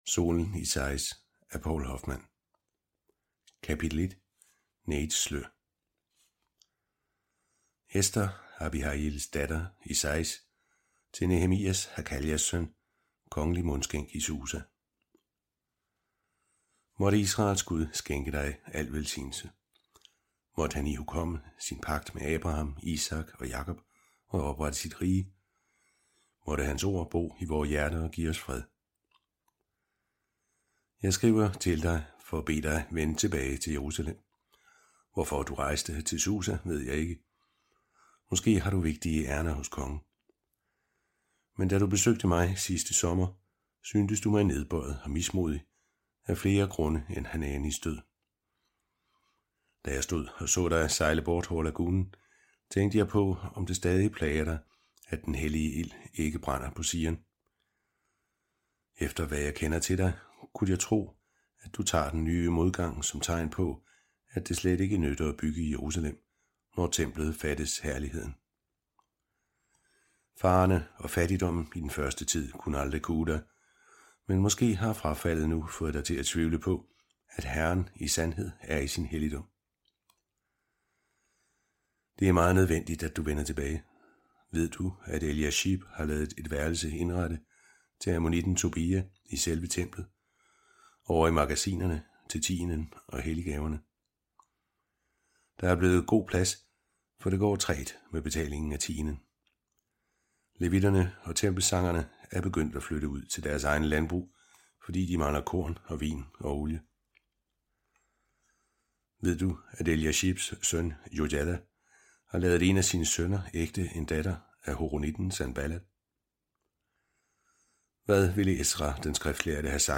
Hør et uddrag af Solen i Saïs Solen i Saïs Ester III Format MP3 Forfatter Poul Hoffmann Lydbog 149,95 kr.